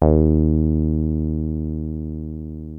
303 D#2 5.wav